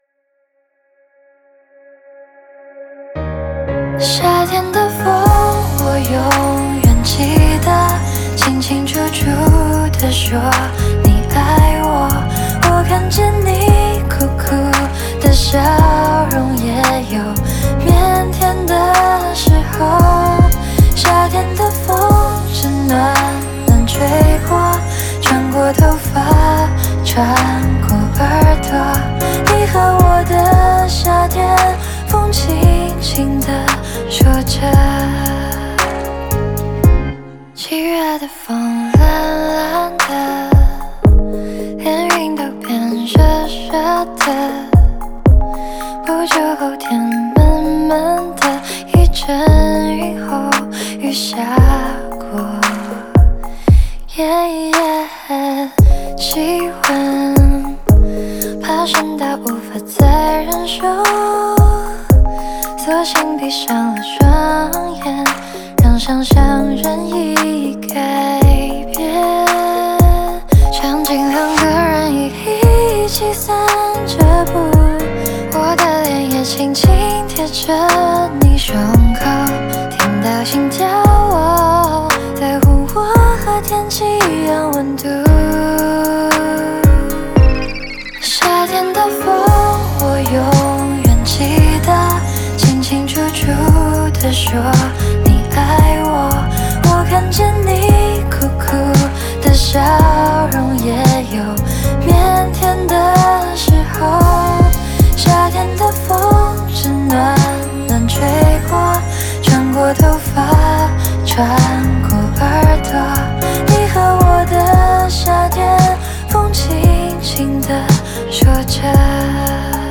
Ps：在线试听为压缩音质节选，体验无损音质请下载完整版
和声